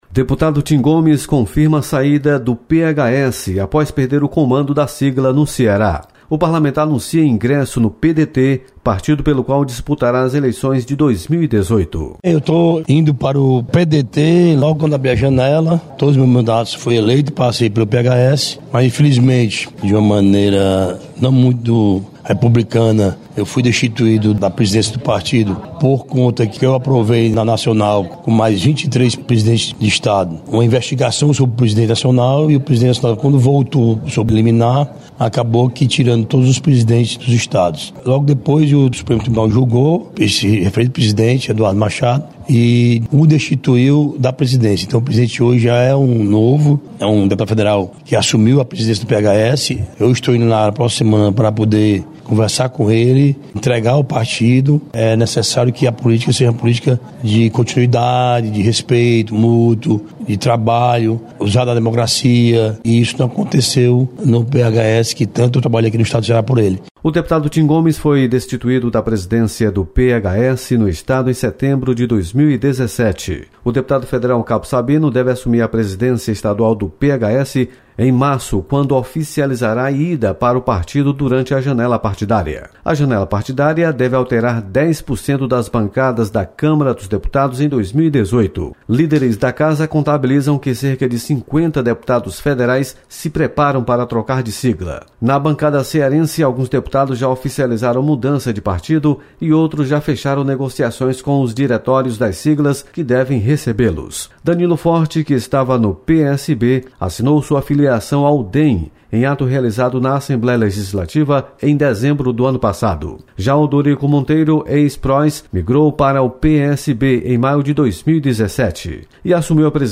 • Fonte: Agência de Notícias da Assembleia Legislativa